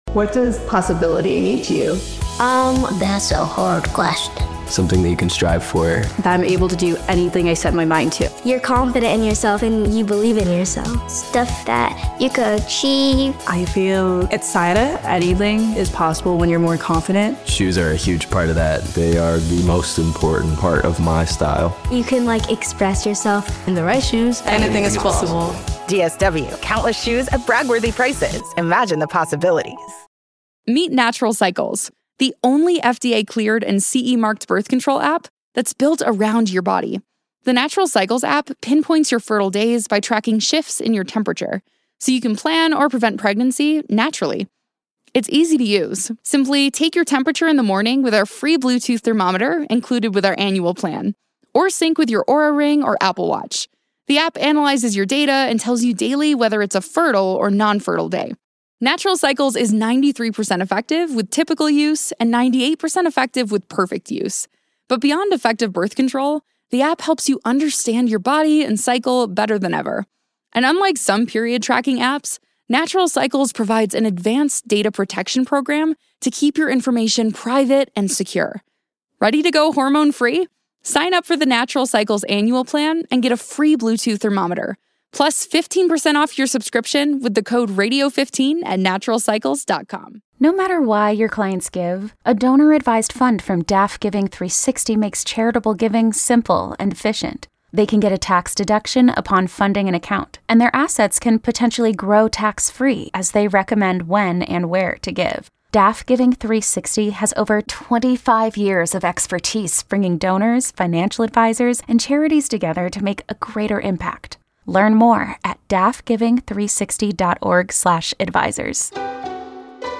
Mike Vrabel was injured breaking up a fight at Patriots camp. The Moment of The Day features Boomer encountering "mutants" in Times Square, and a caller complains about Lindor's "My Girl" walk-up music given his strikeouts.